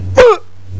scream8.wav